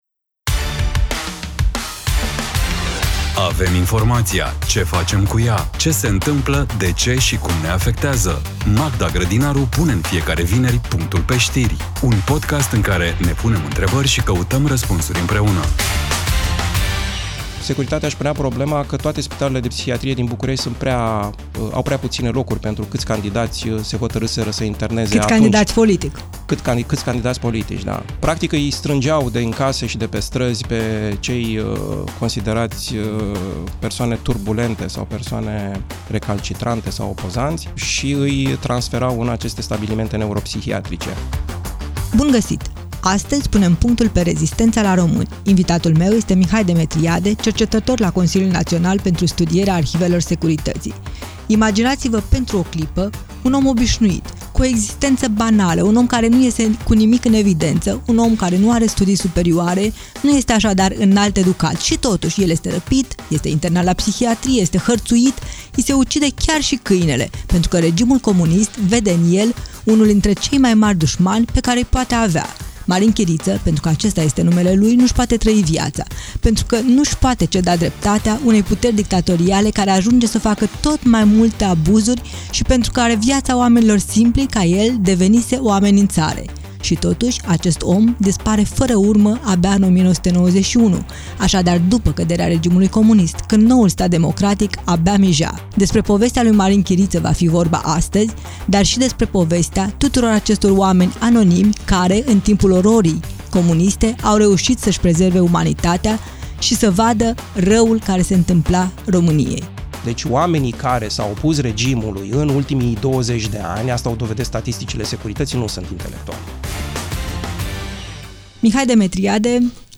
Un dialog inteligent, relaxat și necesar.